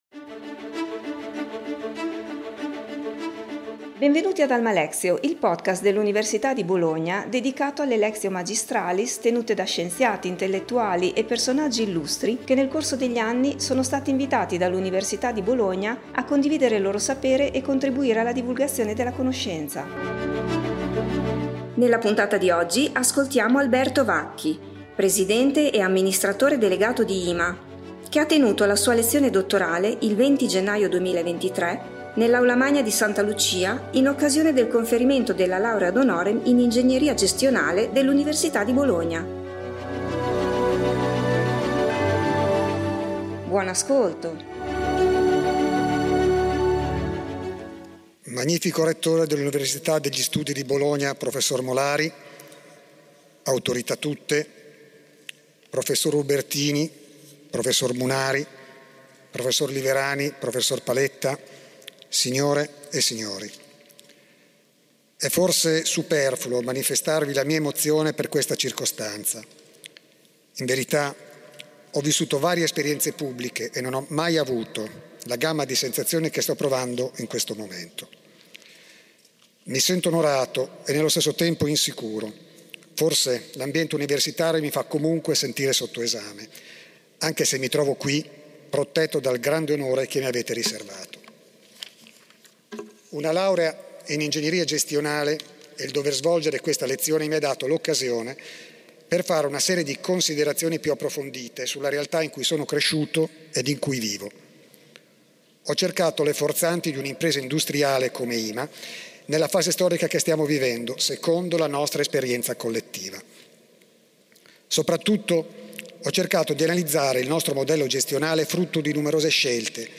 lezione dottorale